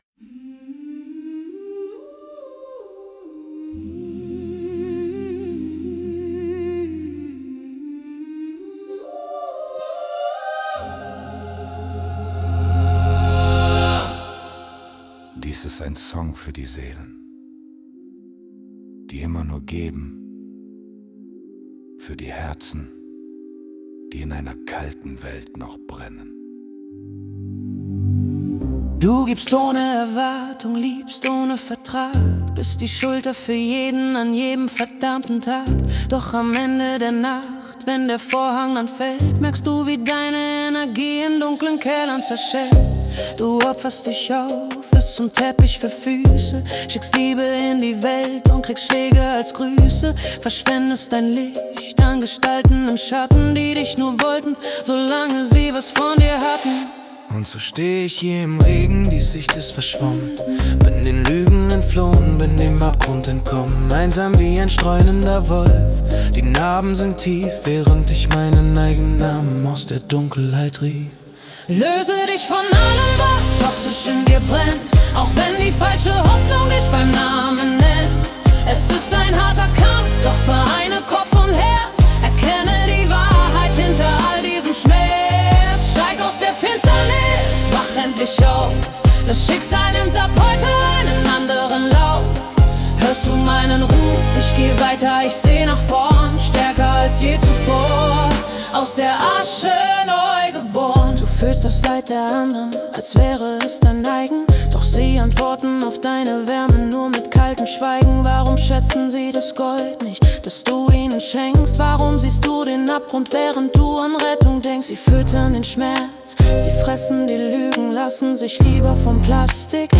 Pop, Choir, Mystery, MailFemale Duett